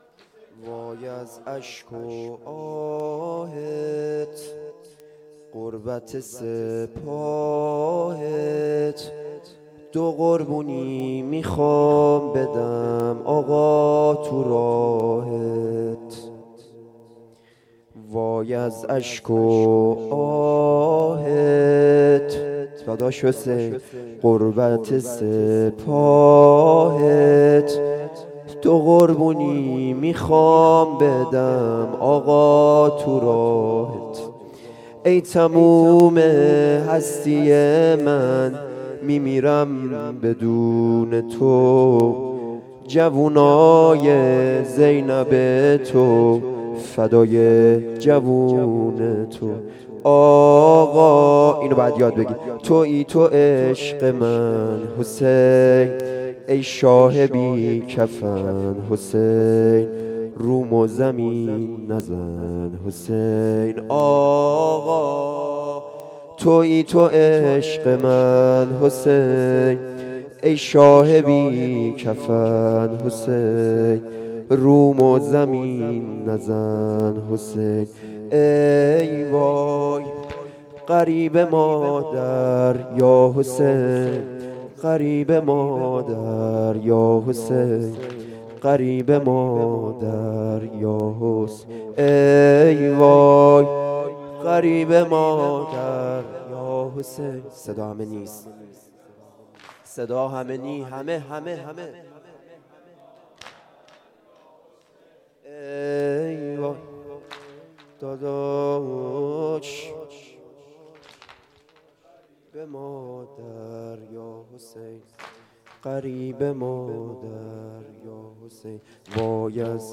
شب چهارم ماه محرم